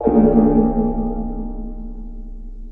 Play TARDIS Cloister Bell - SoundBoardGuy
Play, download and share TARDIS Cloister Bell original sound button!!!!
tardis-cloister-bell.mp3